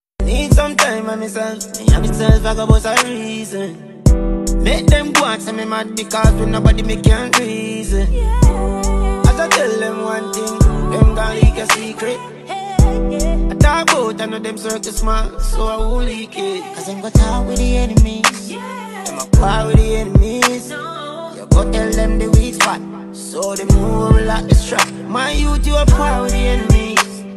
Hmmmmmm sound effects free download